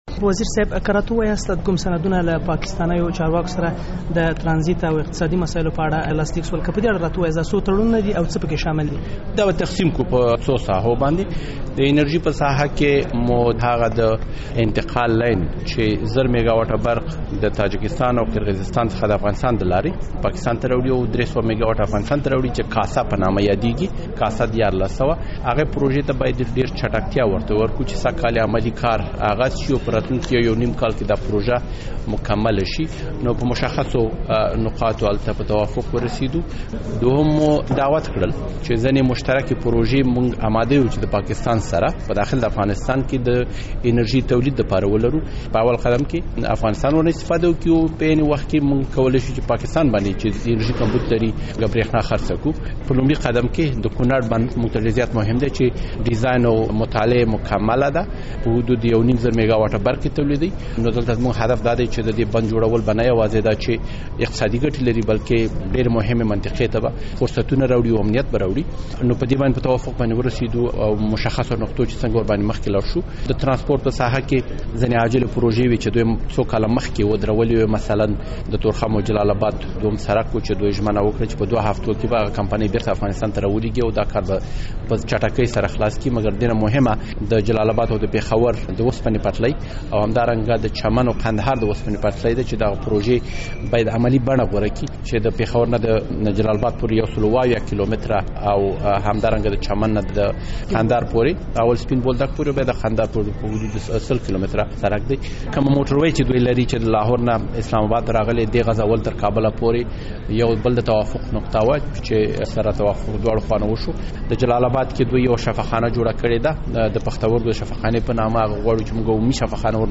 له عمر زاخېوال سره مرکه